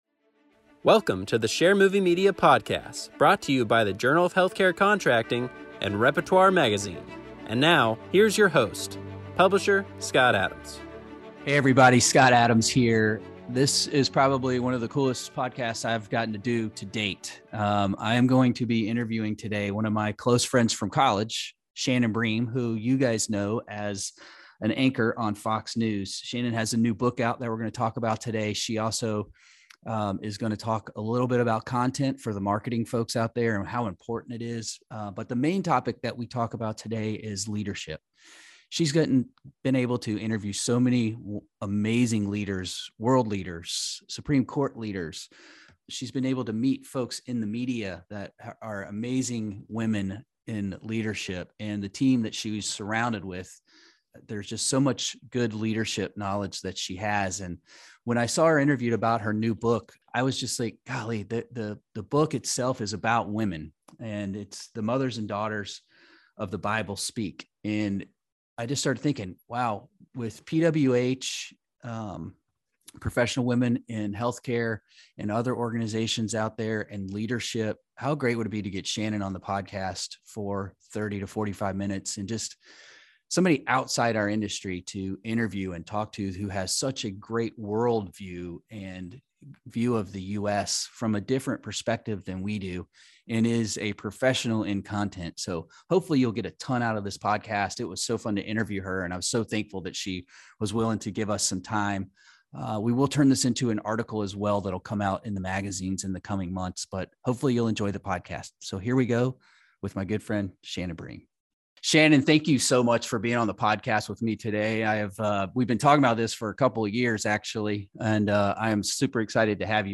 Podcast with Fox News’ Shannon Bream